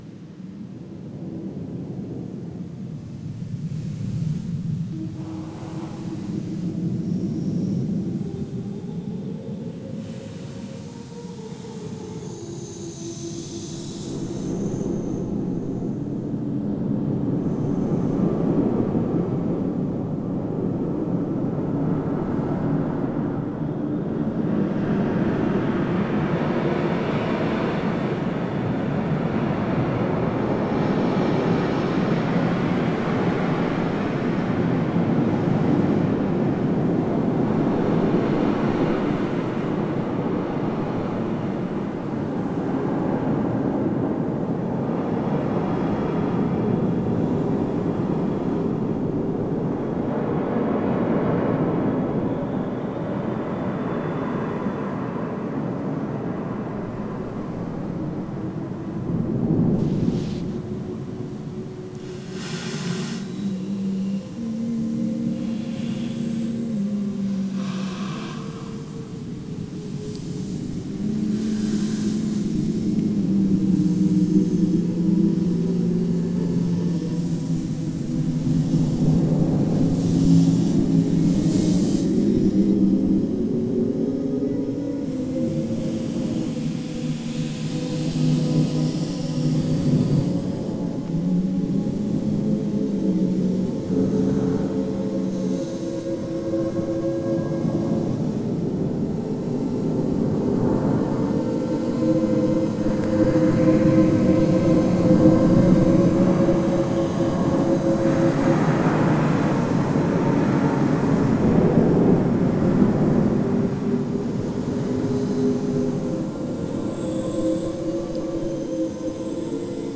I’ve walked.  I’ve hummed… and I have let nature take its course.